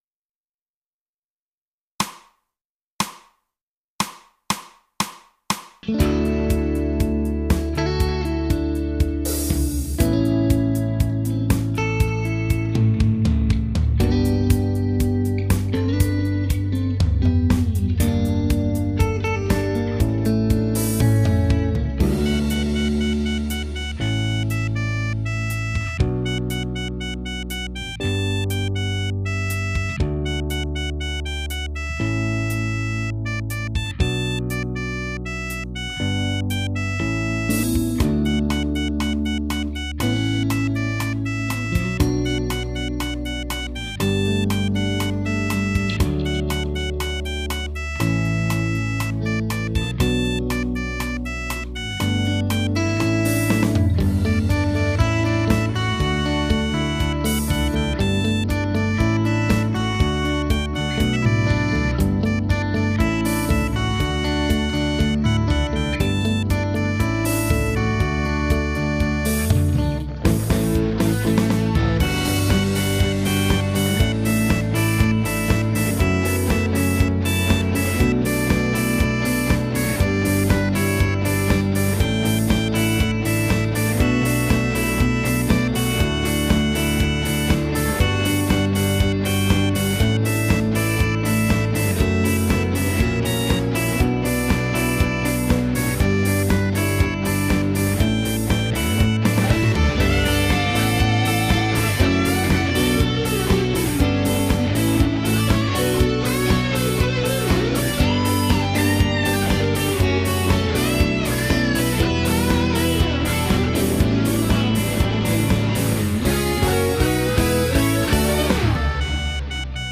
ベース入りMP3ファイル
ベースを>>236さんに弾いてもらいました！